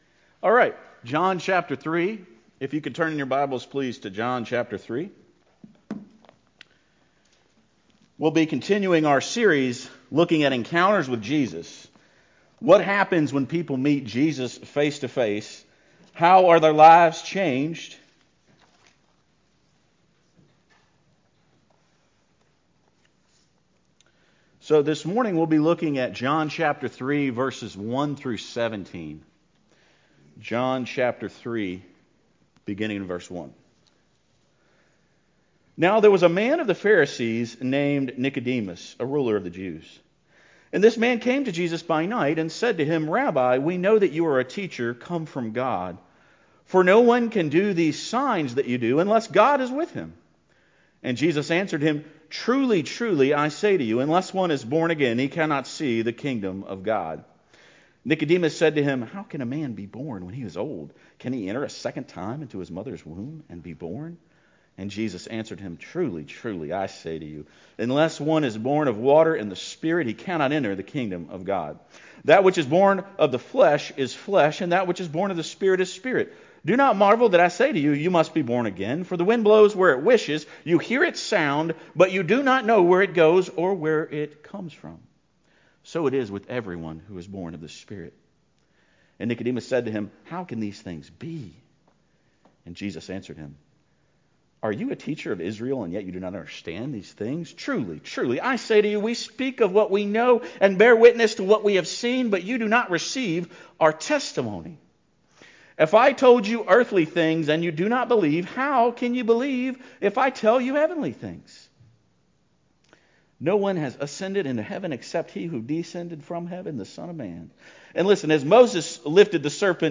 Encounters with Jesus Sunday Morning